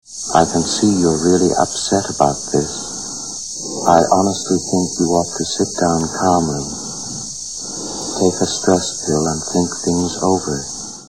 OM MOVIES-Dialogos y parlamentos de peliculas en ingles-Movie Conversations in English